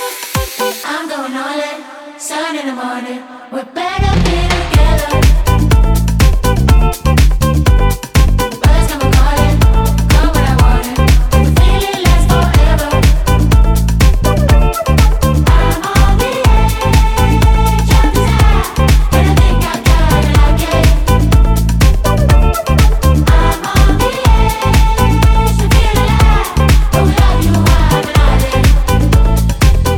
Dance House
Жанр: Танцевальные / Хаус